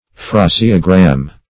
Search Result for " phraseogram" : The Collaborative International Dictionary of English v.0.48: Phraseogram \Phra"se*o*gram\, n. [Gr.